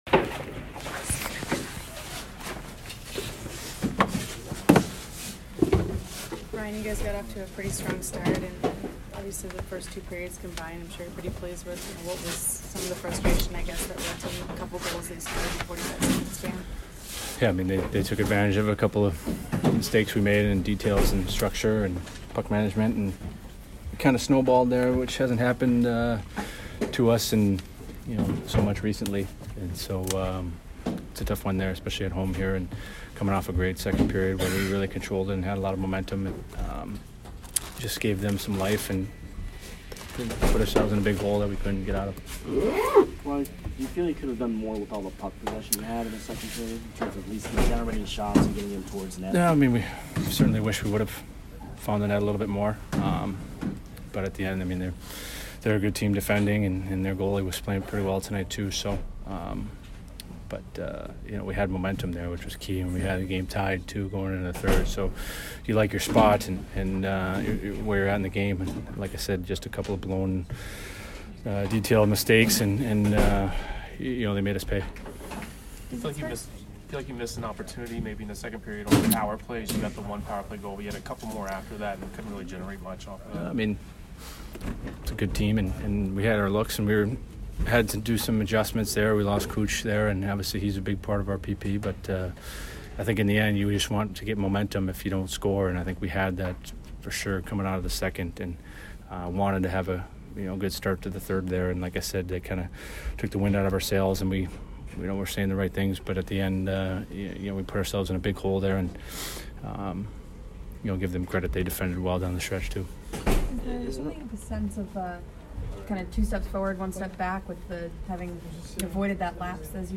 McDonagh post-game 12/14